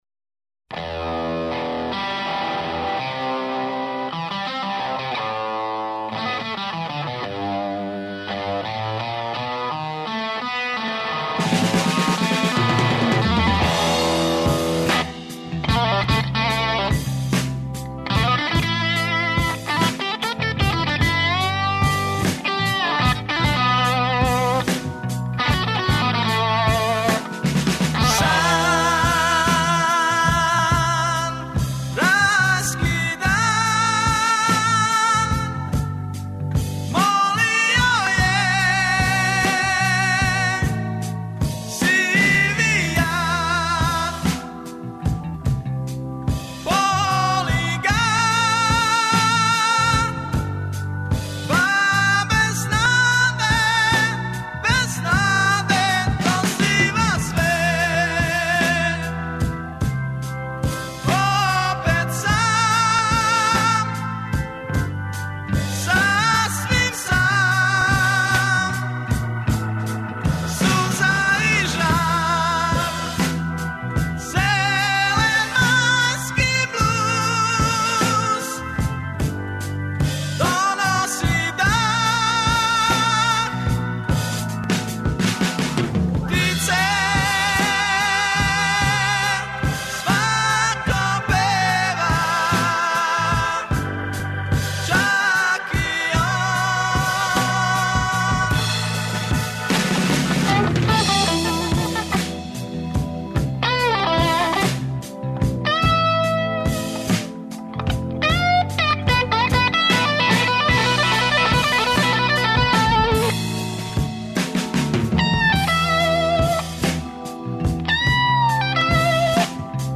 Емитоваћемо јединствен музички материјал, који, захваљујући техничком сектору, чини непроцењиво богатство и идентитет Радио Београда.